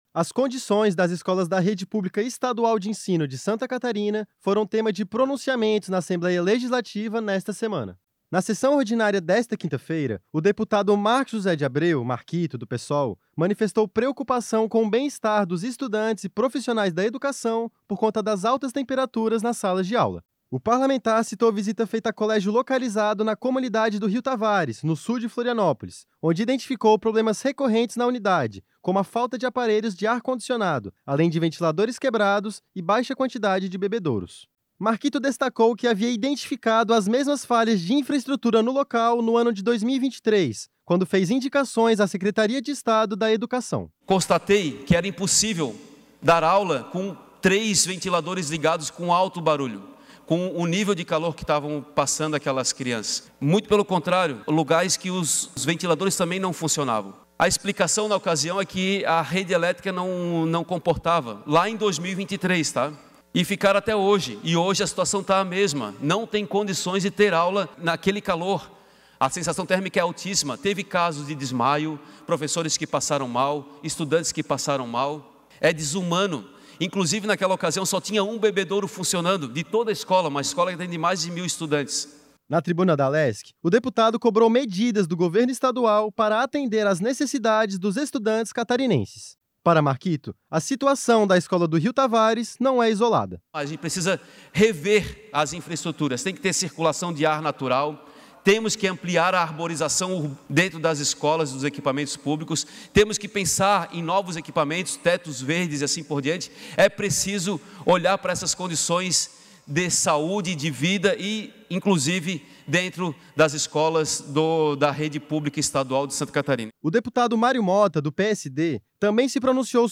Entrevistas com:
- deputado Marcos José de Abreu - Marquito (PSOL);
- deputado Mário Motta (PSD).